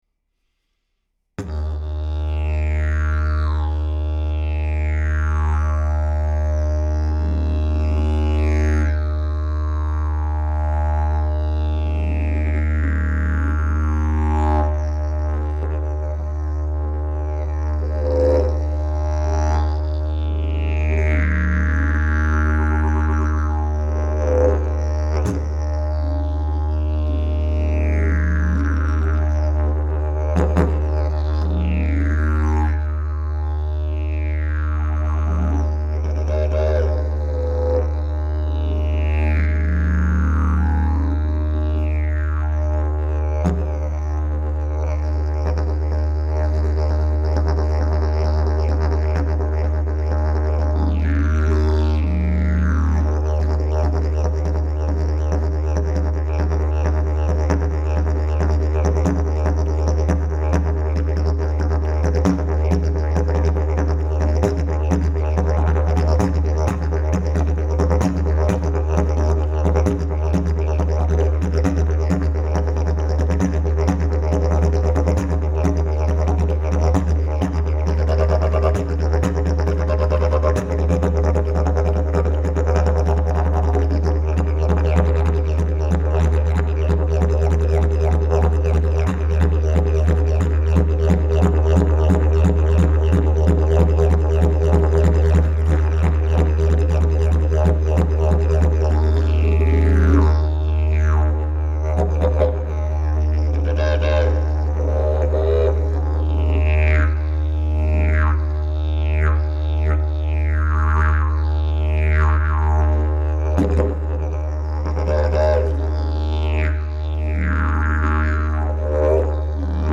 Key: D# Length: 61" Bell: 5" Mouthpiece: Bloodwood, Arizona Oak Back pressure: Very strong Weight: 4 lbs Skill level: Any
Didgeridoo #674 Key: D#